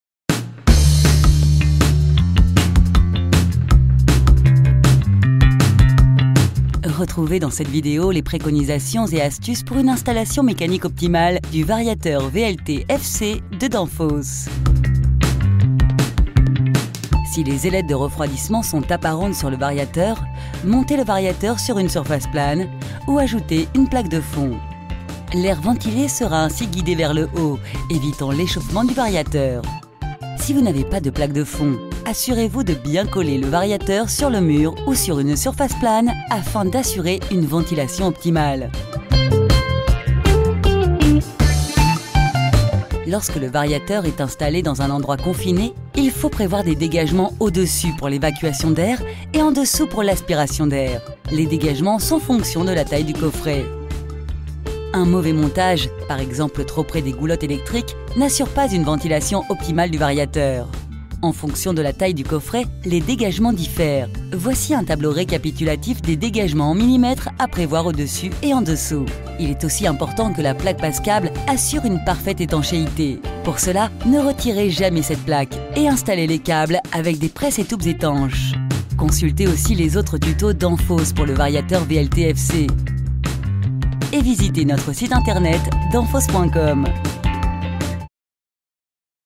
Naturelle, Fiable, Mature, Amicale
E-learning
She works from her personal studio so that your projects are recorded in the best possible quality.